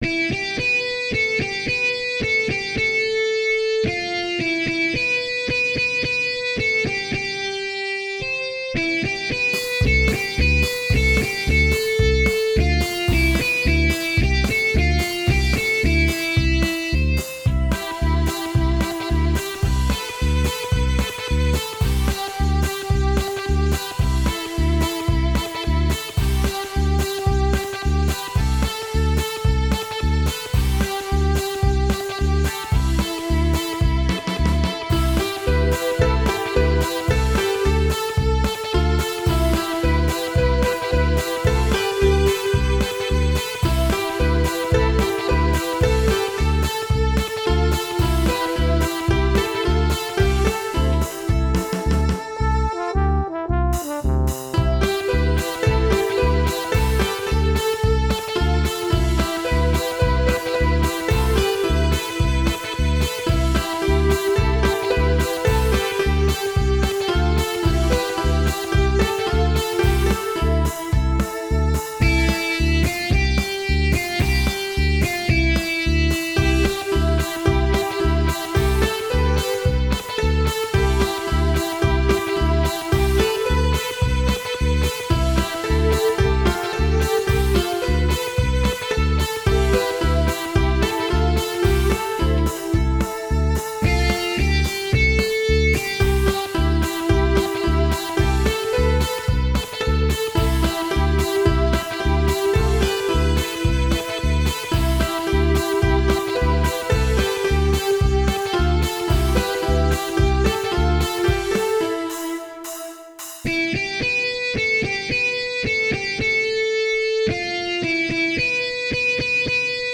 с помощью компьютера и синтезатора
Инструментальная версия